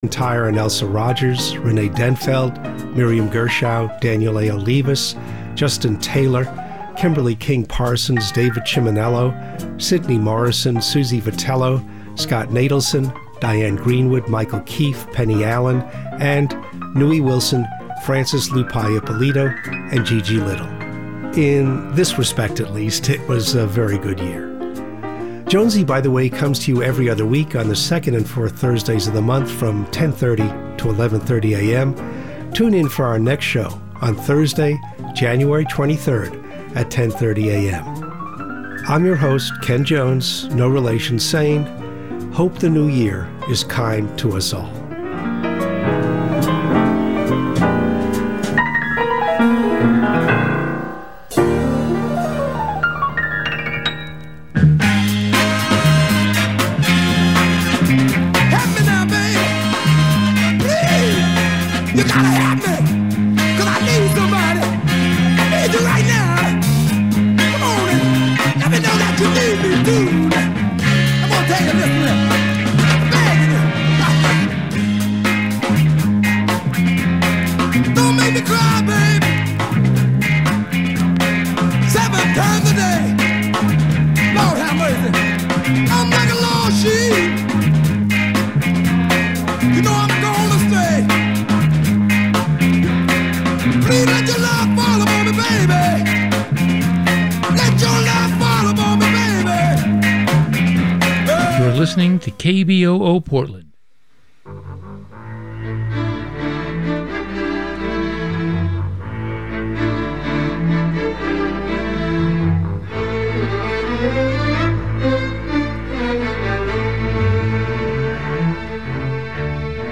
This conversation took place in the KBOO studios